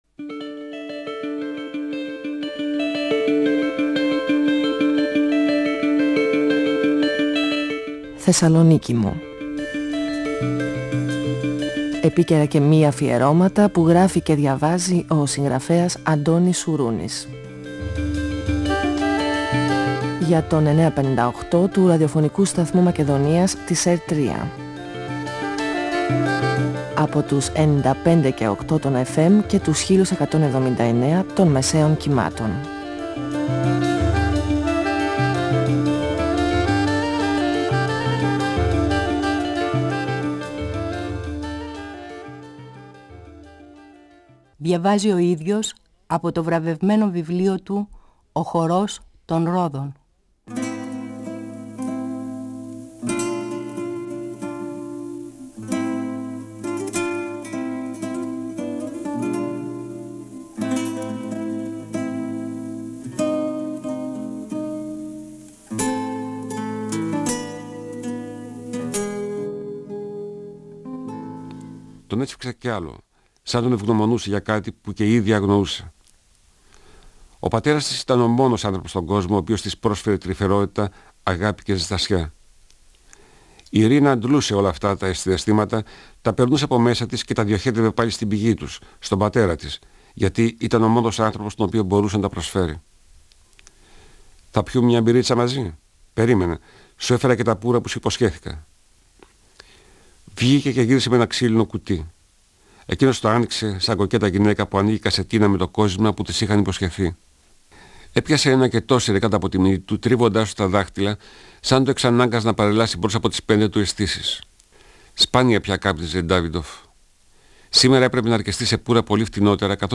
Ο συγγραφέας Αντώνης Σουρούνης (1942-2016) διαβάζει το πρώτο κεφάλαιο από το βιβλίο του «Ο χορός των ρόδων», εκδ. Καστανιώτη, 1994. Η Ιρίνα εξομολογείται στον πατέρα της τον έρωτά της για τον Νούση. Η ρωσική καταγωγή της Ιρίνα και τα παράξενα παιγνίδια της μοίρας.